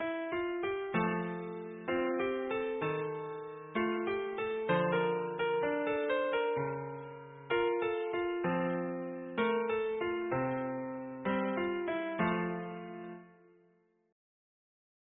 -Music Box Songs,